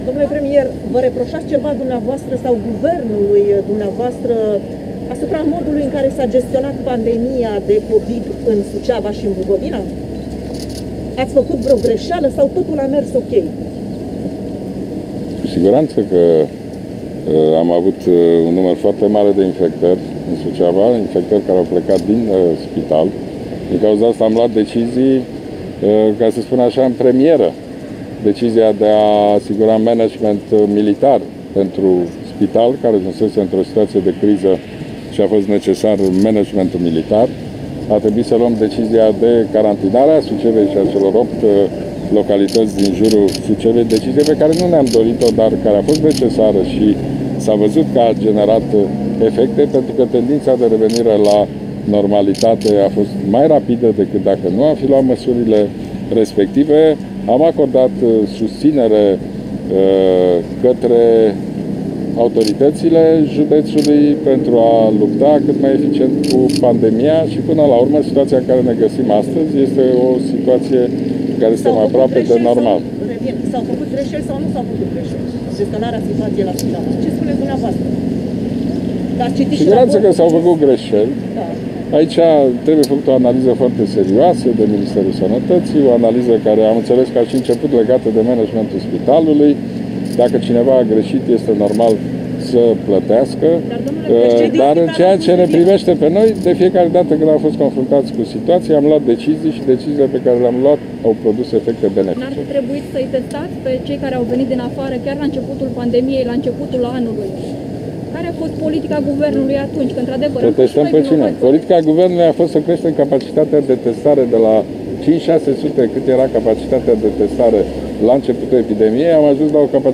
Aflat la Suceava, Ludovic Orban și-a exprimat speranța că rata de răspândire a noului coronavirus va scădea până în toamnă.
orban-la-suceava.mp3